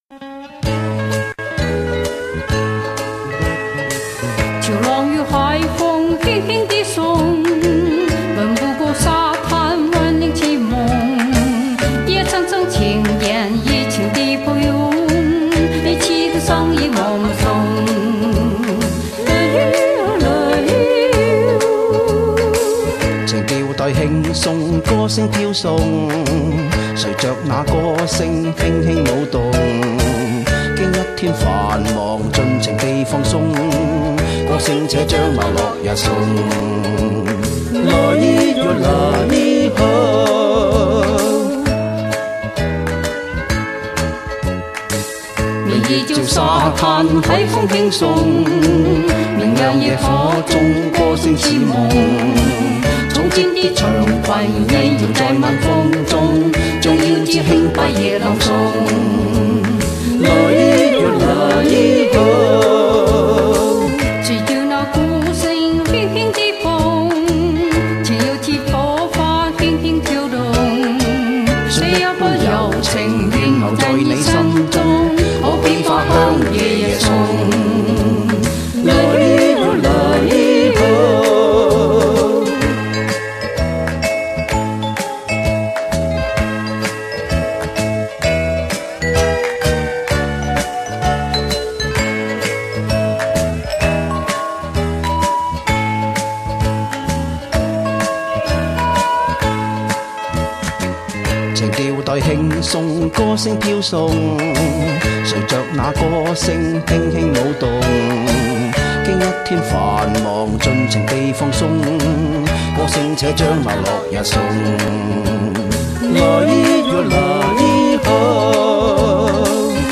唱得很有磁性。